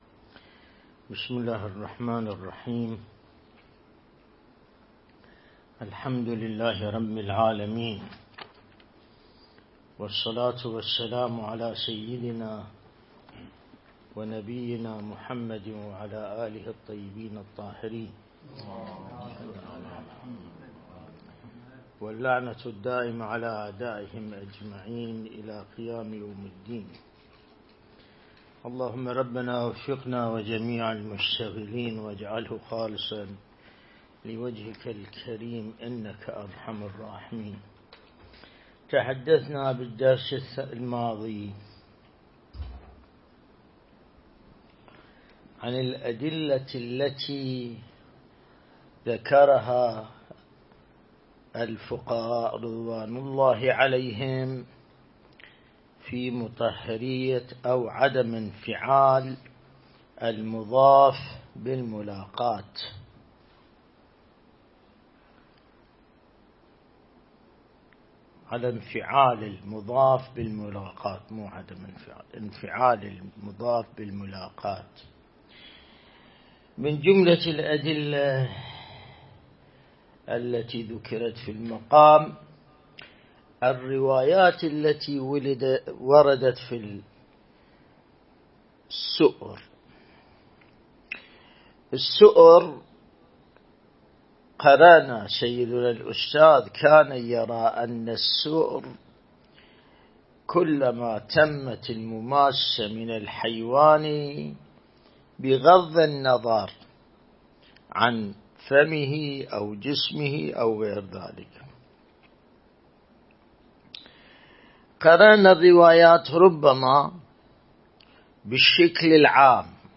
بحث خارج الفقه - كتاب الطهارة(72) | الموقع الرسمي لمكتب سماحة آية الله السيد ياسين الموسوي «دام ظله»
الدرس الاستدلالي شرح بحث الطهارة من كتاب العروة الوثقى لسماحة آية الله السيد ياسين الموسوي (دام ظله)